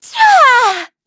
Xerah-Vox_Attack1.wav